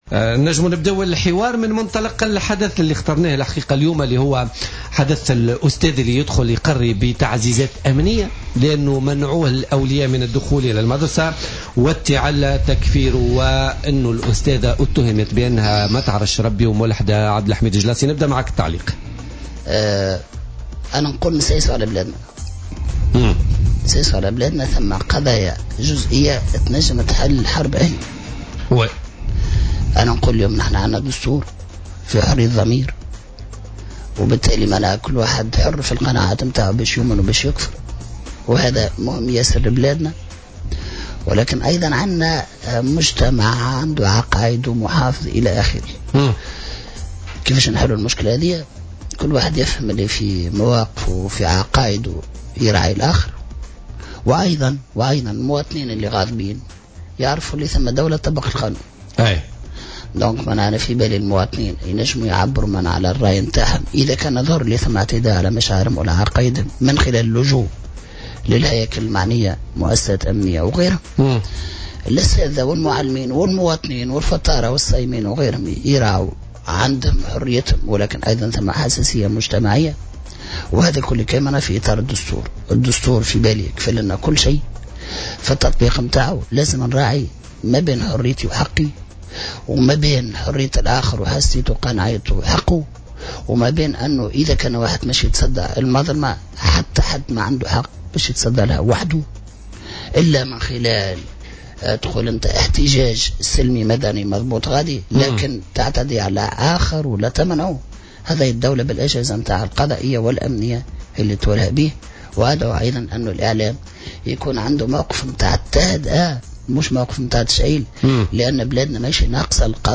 قال القيادي في حركة النهضة عبد الحميد الجلاصي ضيف بوليتيكا اليوم الاثنين 18...